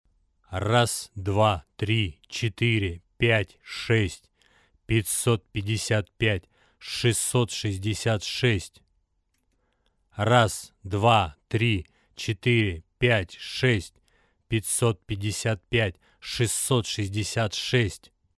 Записал тест. В начале уже перепаяный следом на Лонге развернул фазу.
Может они этим убирают излишний низ )))) !!??
Вложения MC900 phase.mp3 MC900 phase.mp3 288,5 KB · Просмотры: 235